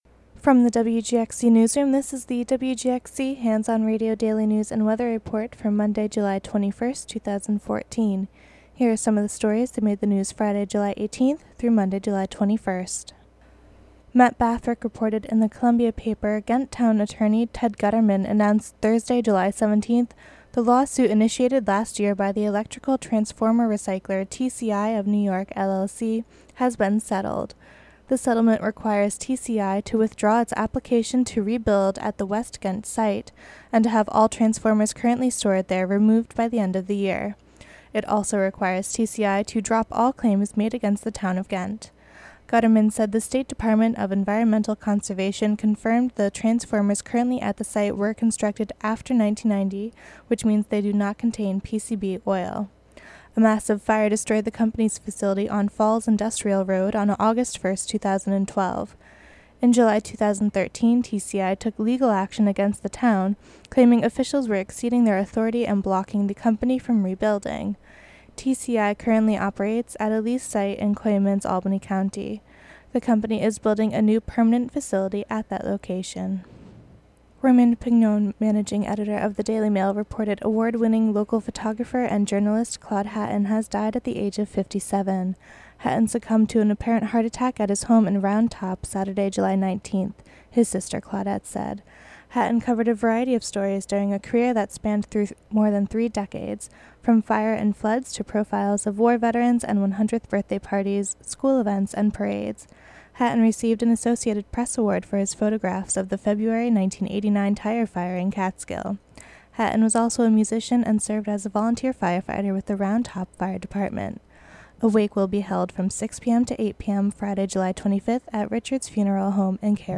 Local news and weather for Monday, July 21, 2014.